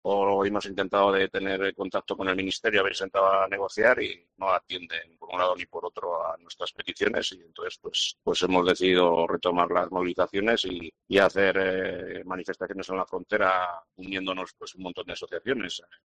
en las protestas